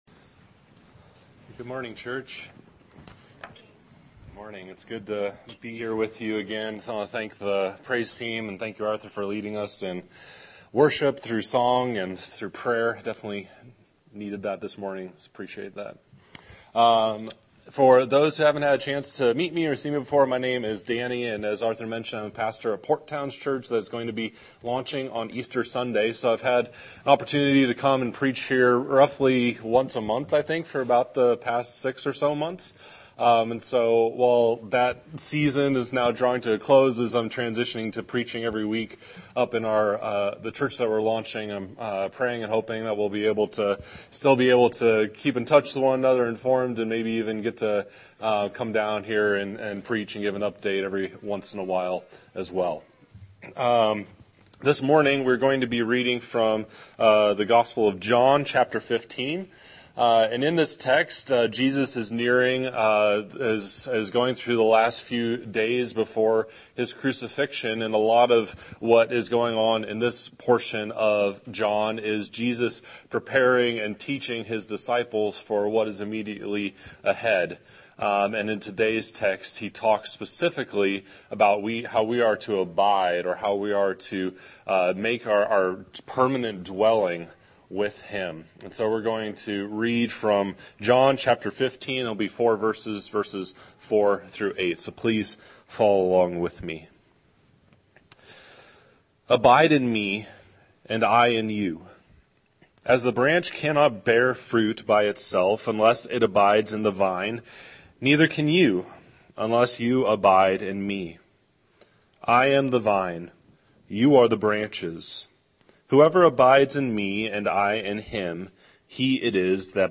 Scripture: John 15:4-8 Series: Sunday Sermon